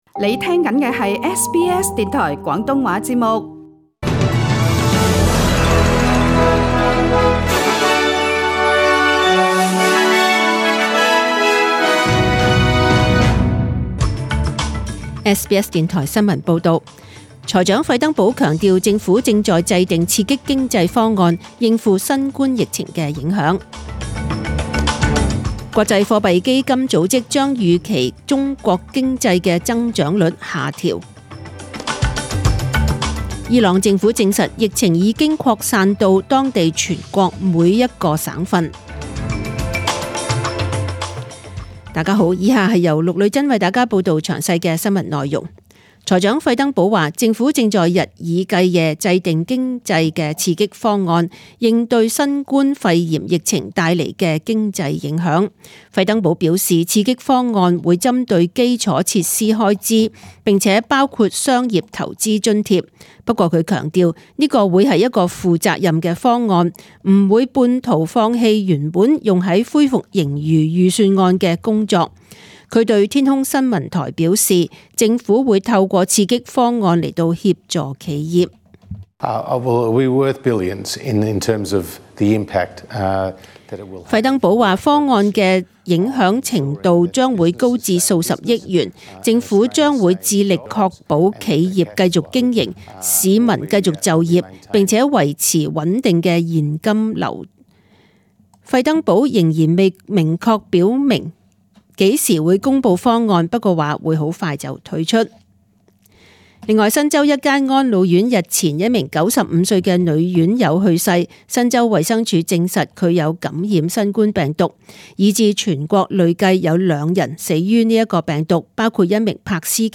请收听本台为大家准备的详尽早晨新闻